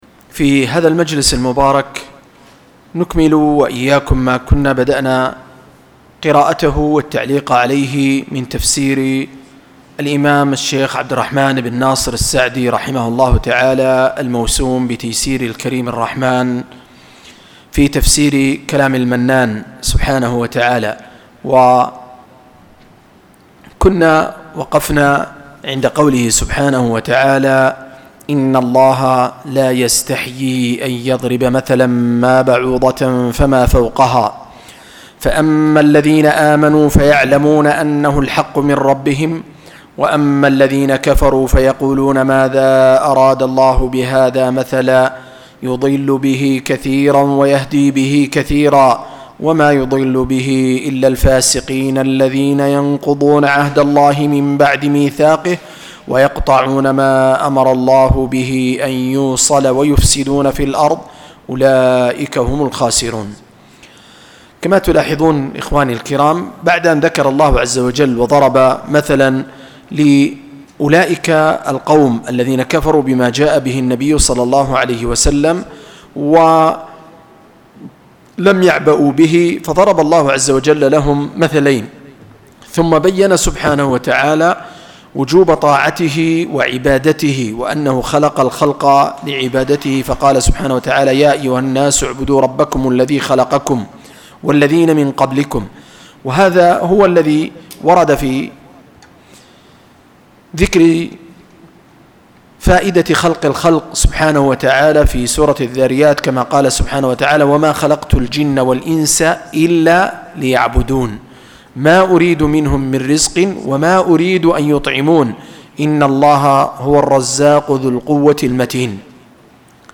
009- التدبر من كتاب تيسير الكريم الرحمن في تفسير كلام المنان ، للشيخ السعدي -رحمه الله- (قراءة وتعليق)